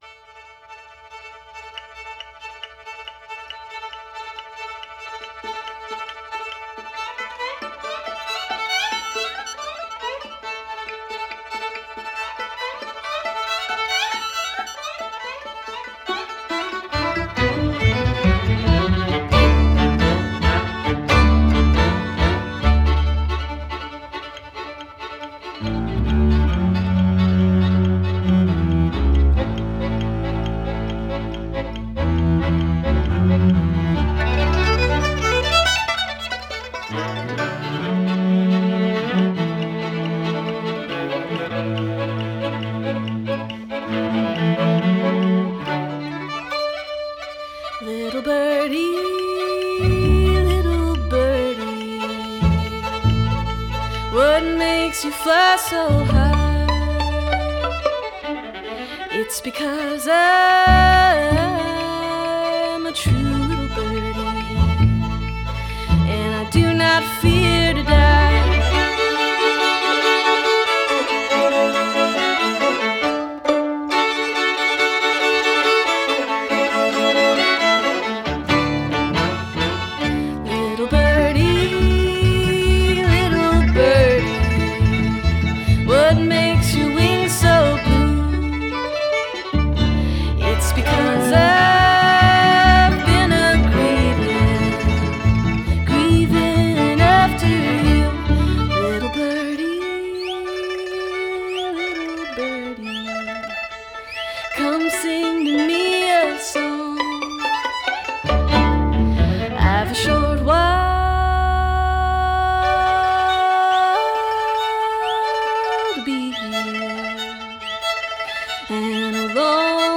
Жанр: Classical.